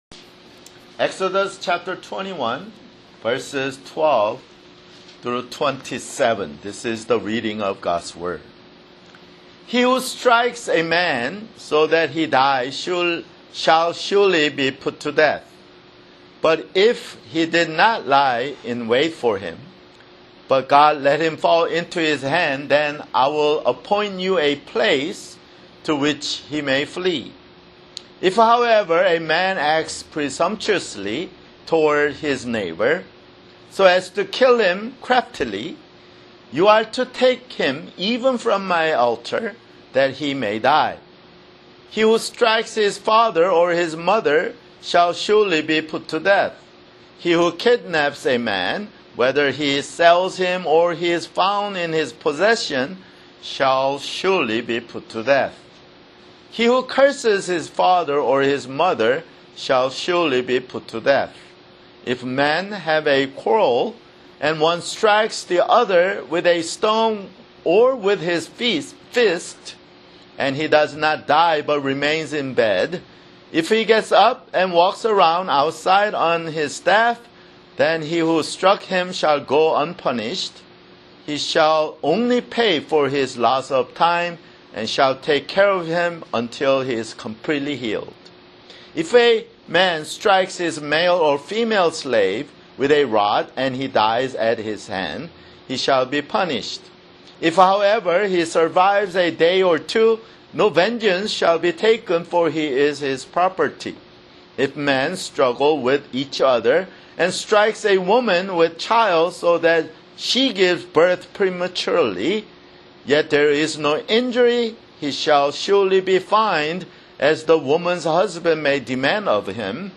[Sermon] Exodus (62)